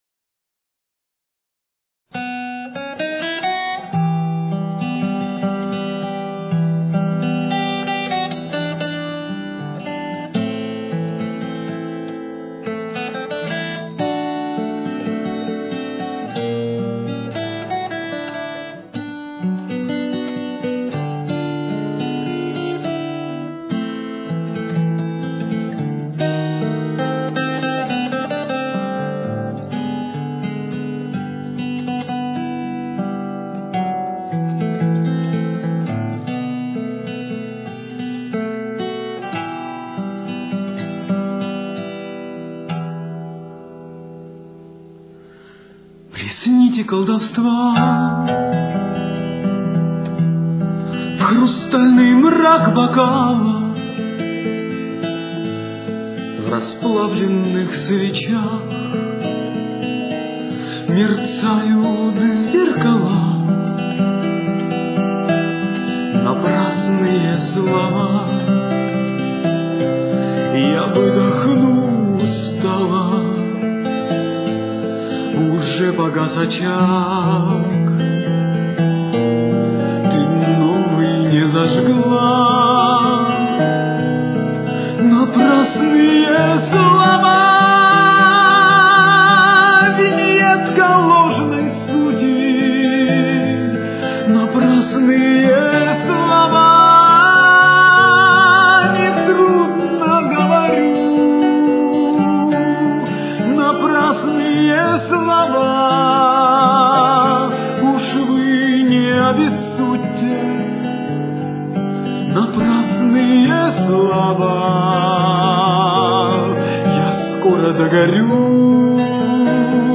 советский и российский певец
Темп: 107.